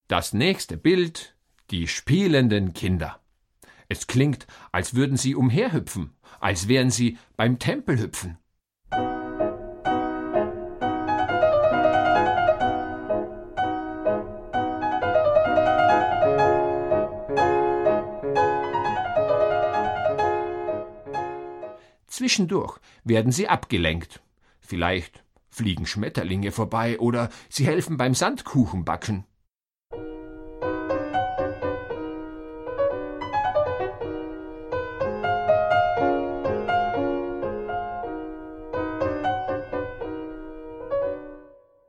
Große klassische Musik für kleine Ohren